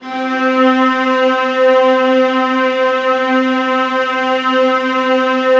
VIOLAS DN4-R.wav